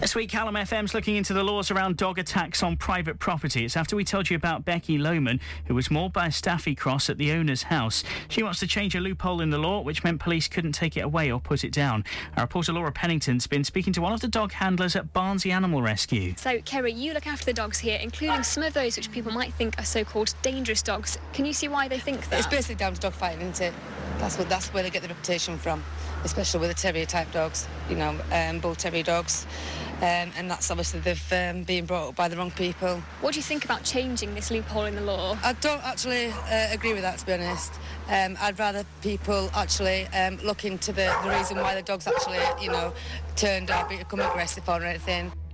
Here I went out to speak to a dog handler at Barnsley Animal Rescue to find out what she thought about the idea.